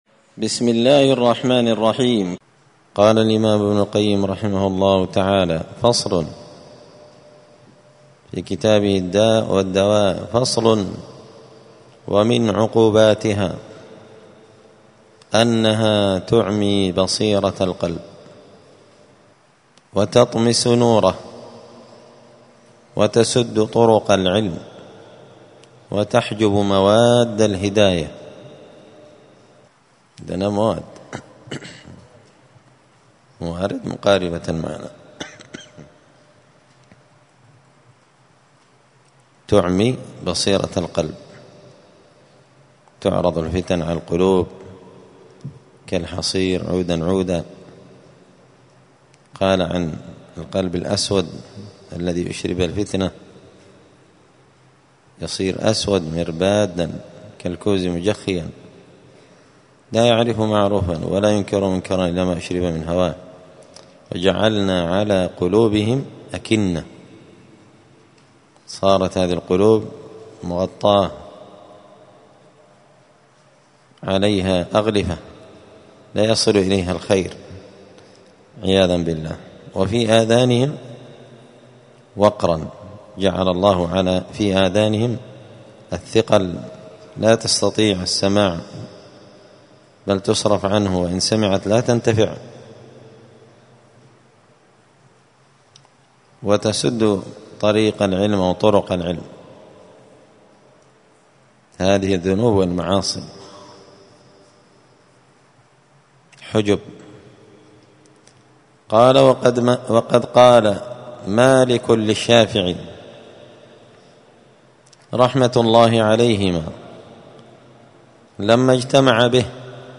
الداء والدواء للإمام ابن القيم رحمه الله الدرس السادس والثلاثون (36) فصل من عقوبات الذنوب والمعاصي أنها تعمي بصيرة القلب وتطمس نوره وتسد طرق العلم